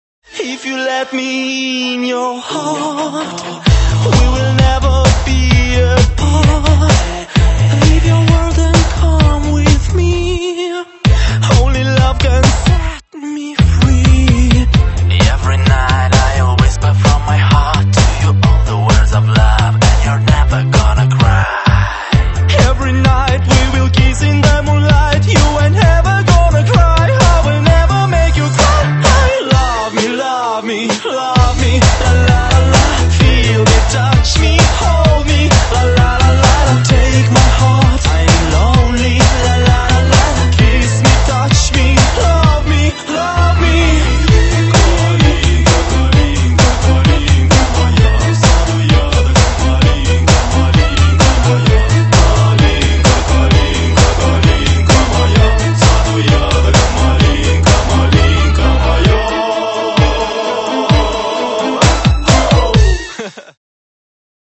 Demo version